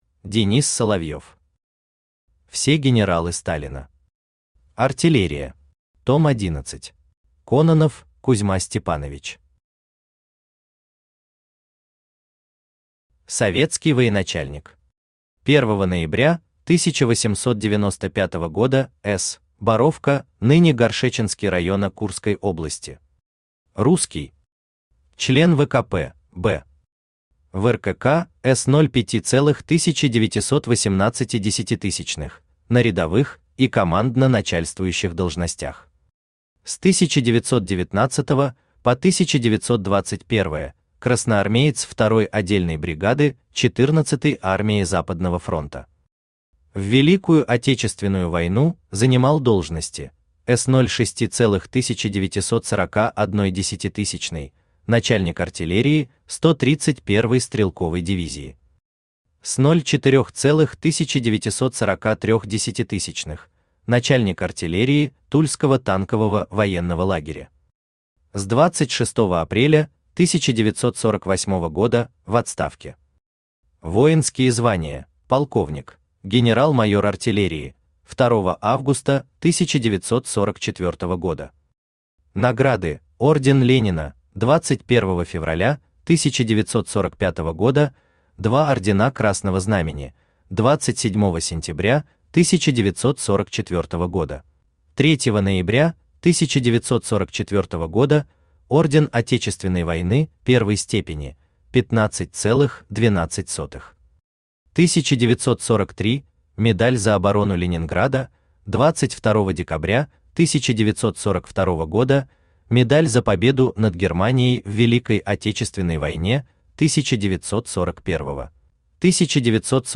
Аудиокнига Все генералы Сталина. Артиллерия. Том 11 | Библиотека аудиокниг
Том 11 Автор Денис Соловьев Читает аудиокнигу Авточтец ЛитРес.